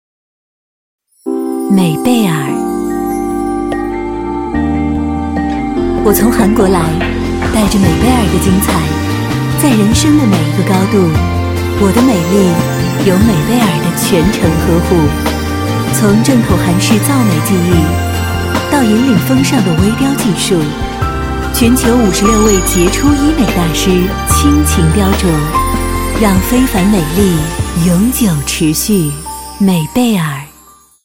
国语青年积极向上 、时尚活力 、神秘性感 、调性走心 、亲切甜美 、女广告 、500元/条女S143 国语 女声 广告 时尚 化妆品 卞卡系列 积极向上|时尚活力|神秘性感|调性走心|亲切甜美